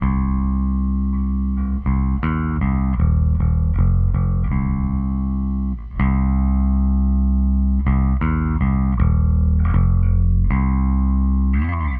摇滚80年代吉他特效
描述：小型循环包只包含核心的摇滚元素。
标签： 80 bpm Rock Loops Fx Loops 2.02 MB wav Key : F
声道立体声